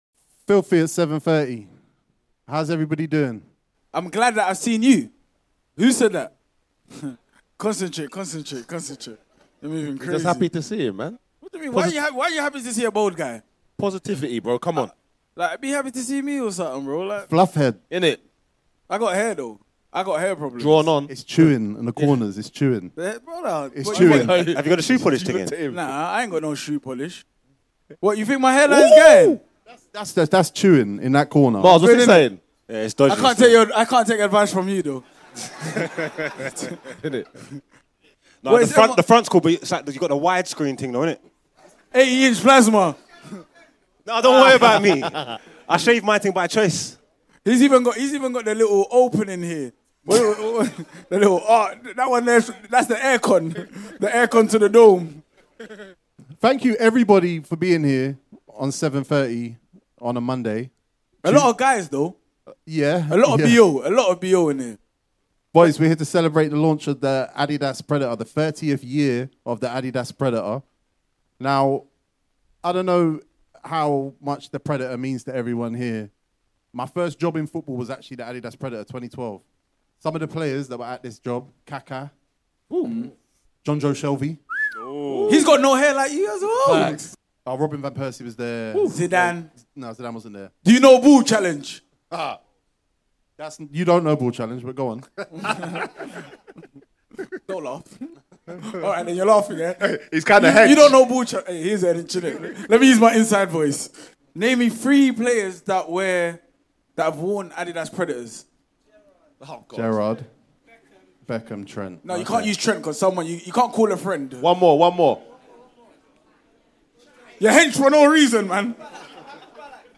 With the iconic adidas Predator turning 30 this year, FILTHY FELLAS were invited down to adidas Stratford to make a live podcast in front of a specially invited audience of Filthy Crowd Members.